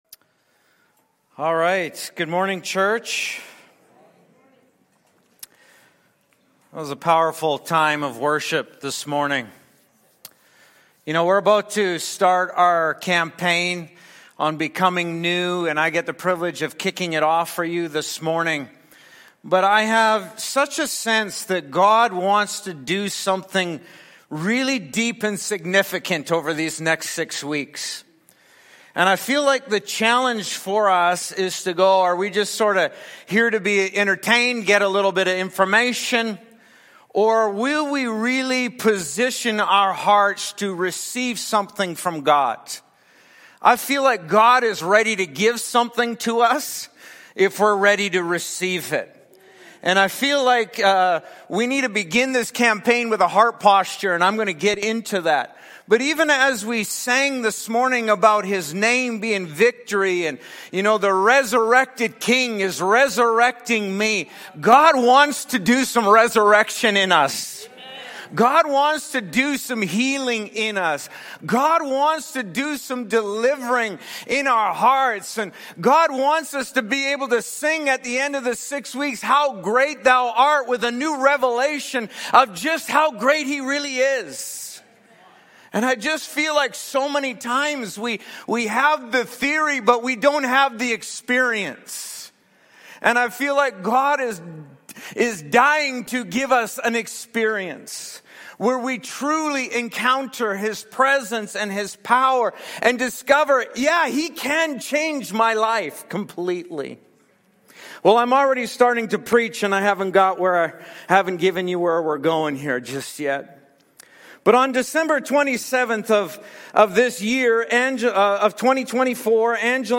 Sunday sermons and topical teachings from the leaders of Harvest City